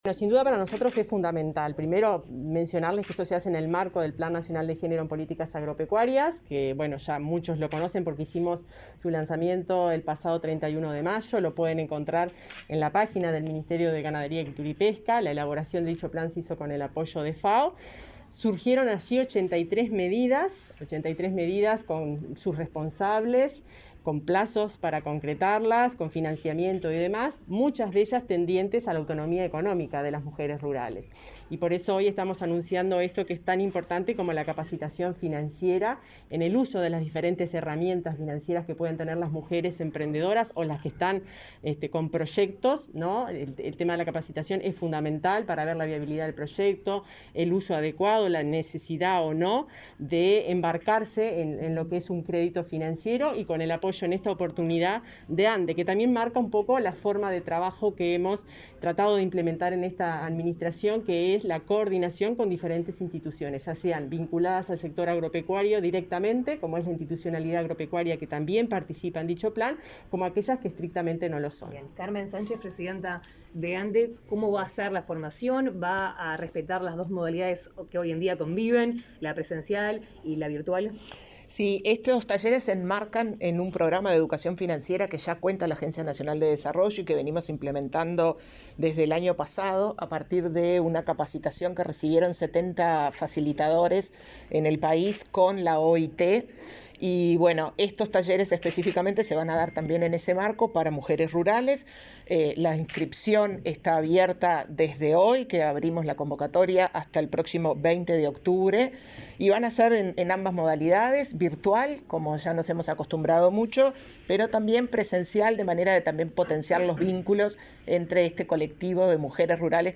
Declaraciones de prensa de autoridades de Ganadería y la ANDE
La directora general del Ministerio de Ganadería, Agricultura y Pesca (MGAP), Fernanda Maldonado, y la presidenta de la Agencia Nacional de Desarrollo (ANDE), Carmen Sánchez, dialogaron con la prensa sobre el taller virtual gratuito que organizan en conjunto, para capacitar en el área financiera a mujeres de zonas rurales, en el marco del Plan Nacional de Género en Políticas Agropecuarias.